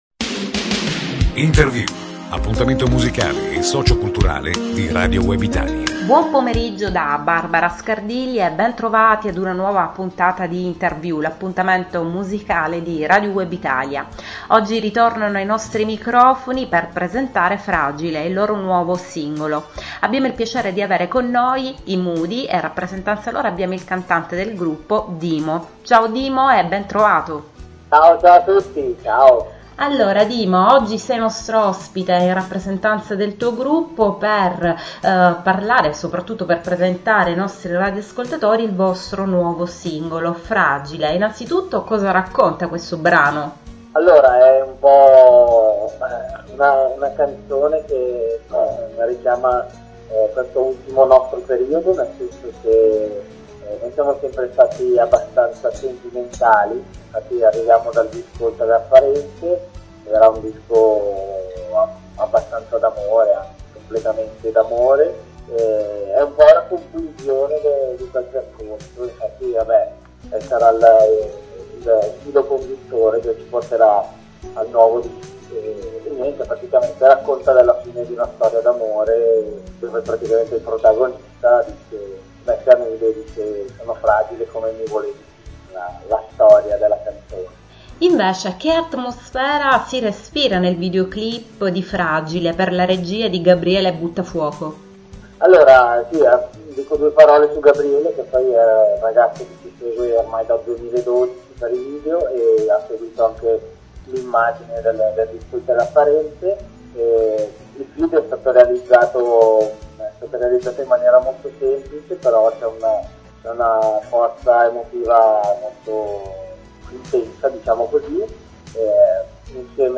moody-intervista-1.mp3